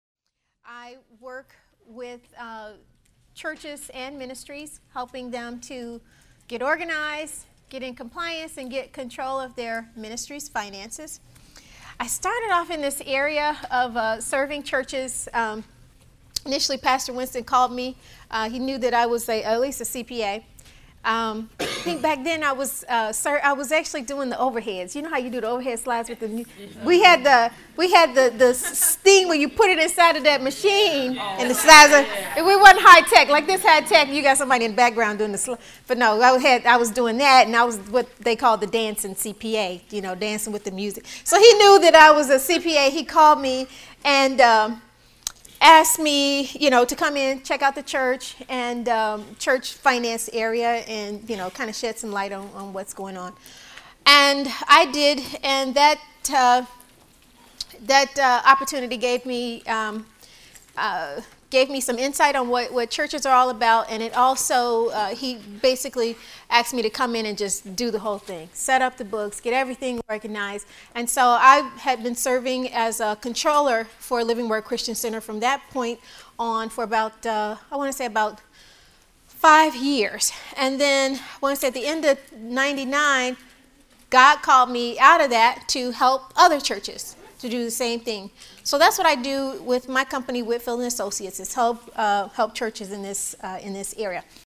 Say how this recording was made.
So whether you’re in full-time ministry impacting the nations or an individual who volunteers their gifts and time to a ministry, you will benefit from the uncommon knowledge and keen insight taught by the ministry leaders in this conference compilation.